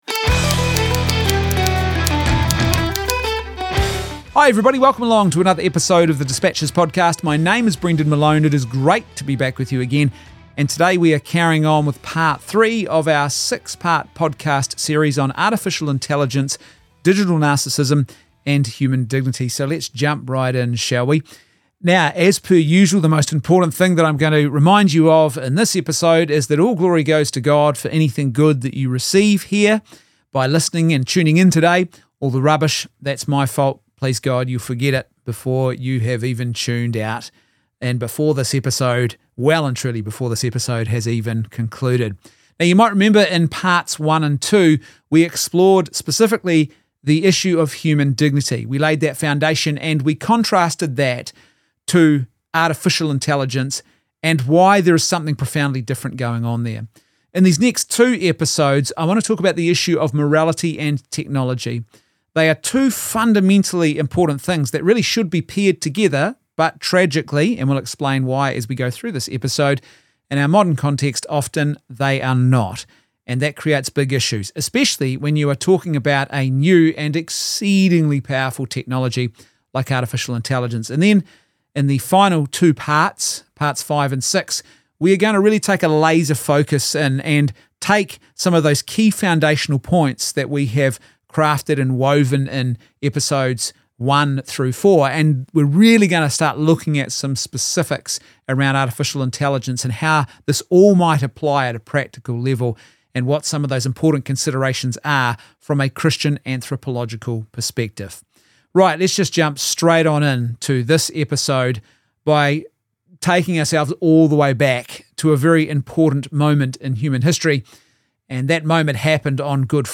The third episode in a special 6 part podcast adaption of a lecture series that I was asked to present at an event in January 2026 on Artificial Intelligence in the light of Christian anthropology. In this episode we begin exploring the issue of technology and morality.